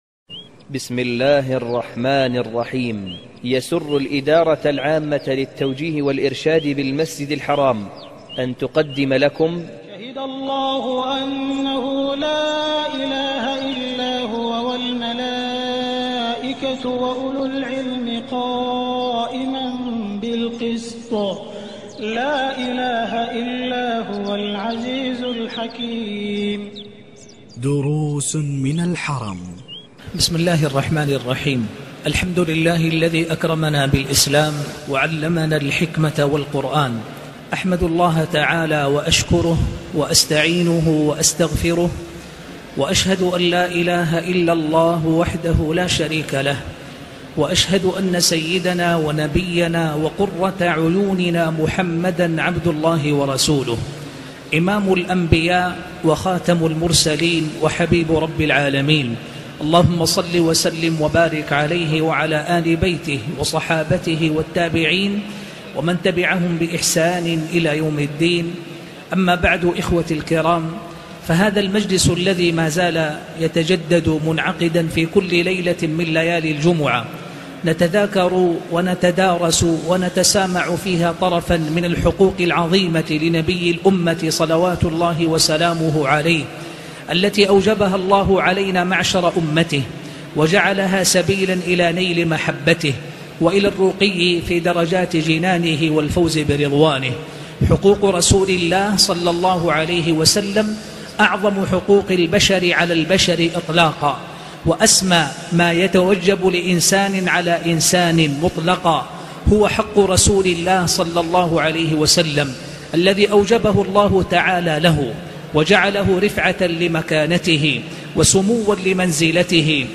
تاريخ النشر ٥ محرم ١٤٣٨ هـ المكان: المسجد الحرام الشيخ